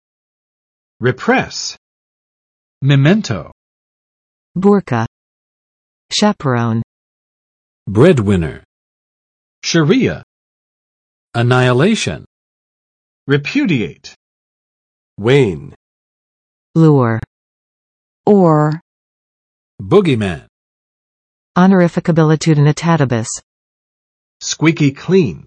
[rɪˋprɛs] n. / v.（尤指用武力）镇压，压制，制止